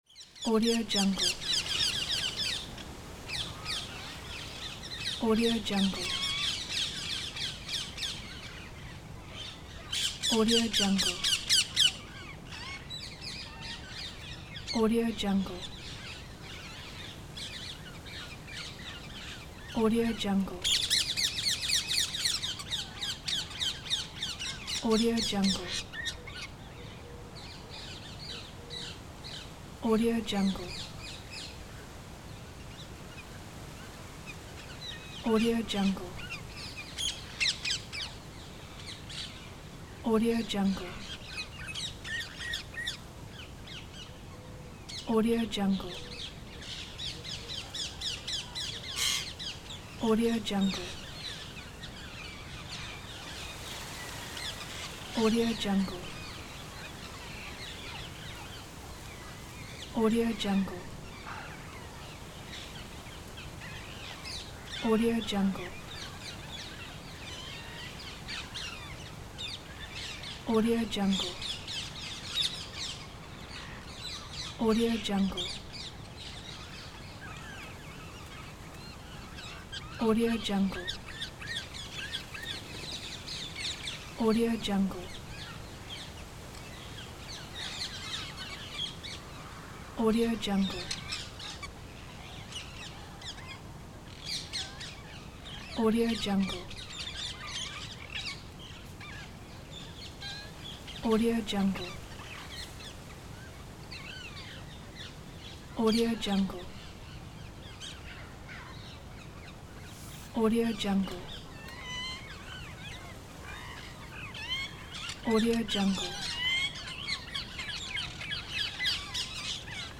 دانلود افکت صوتی صدای طوطی ها در پارک
دانلود افکت صوتی صدای طوطی ها در پارک Parrots In Park Ambiance royalty free audio track is a great option for any project that requires urban sounds and other aspects such as a parrots, park and parakeets.
16-Bit Stereo, 44.1 kHz